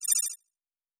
Sci-Fi Sounds / Interface / Data 25.wav